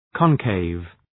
Προφορά
{kɒn’keıv}